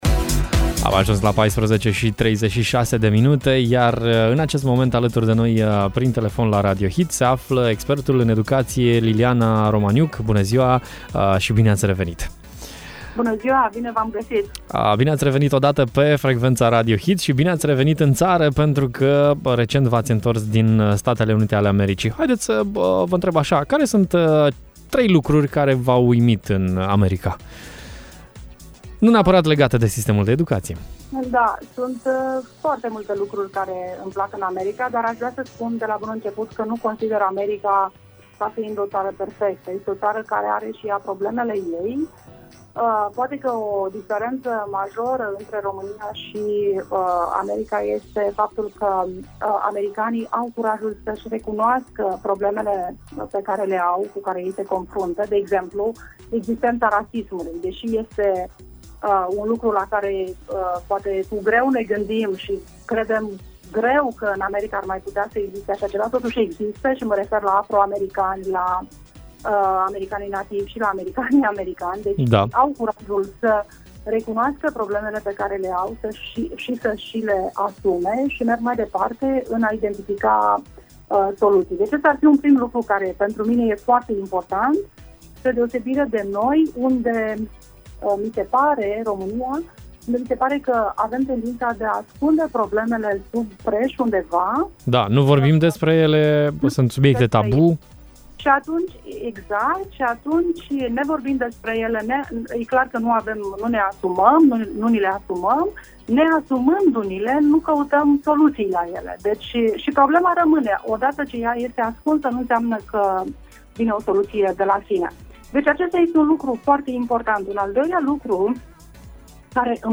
Ce putem să importăm din sistemul educațional american? O discuție